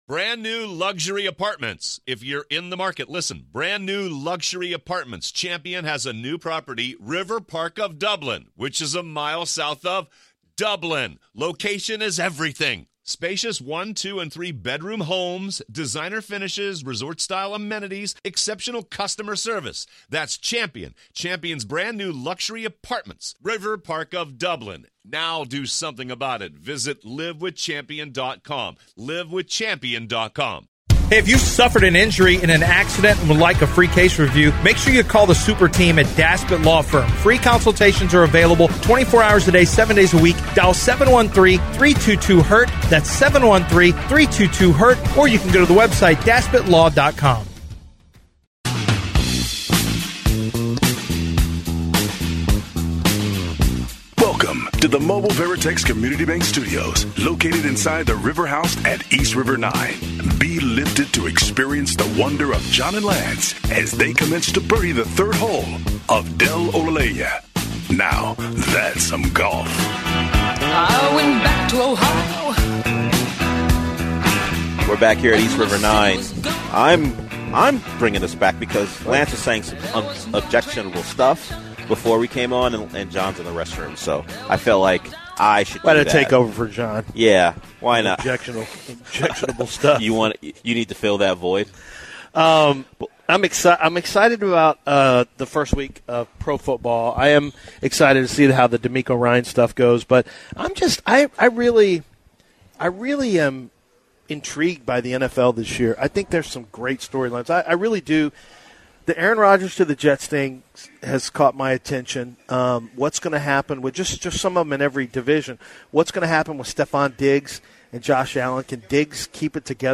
Live from East River 9